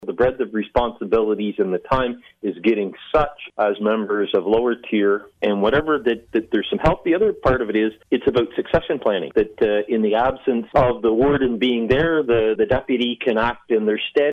Current Warden Brent Devolin says there are benefits to having a deputy.